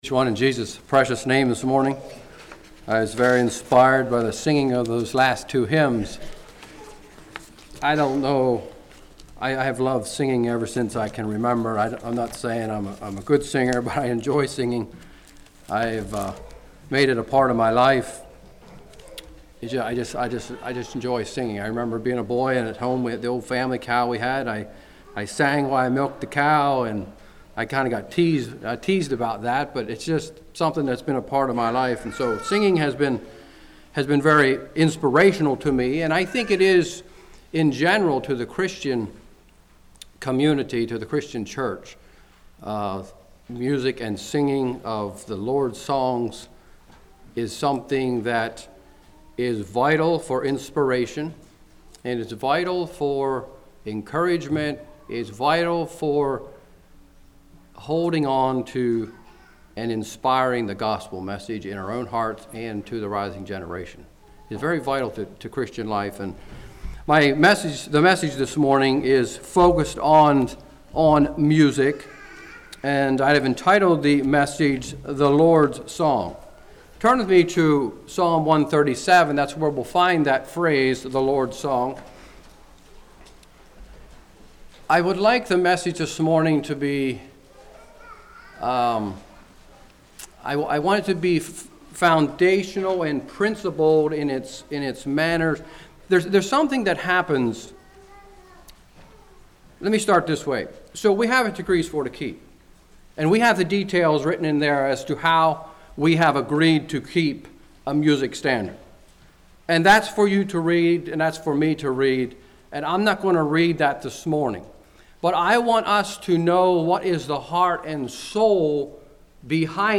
Play Now Download to Device The Lord's Song Congregation: Blue Ridge Speaker